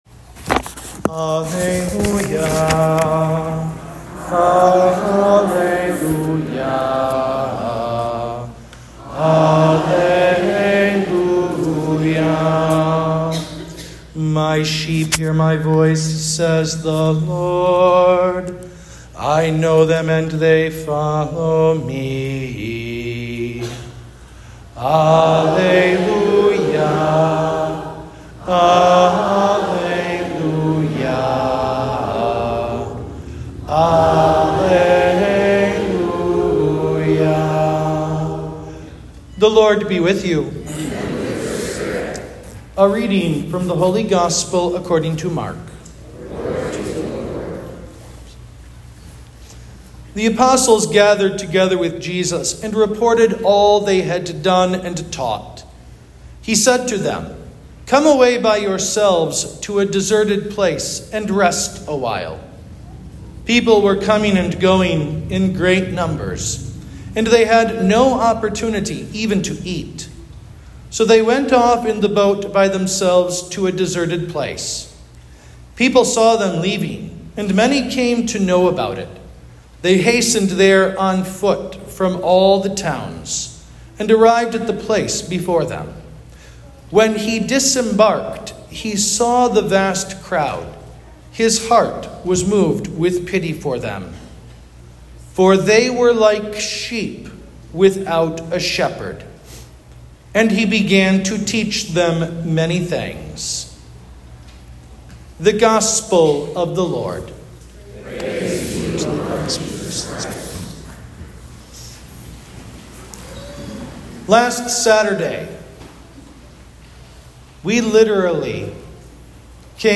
Homily-16th Sunday of Ordinary Time (B)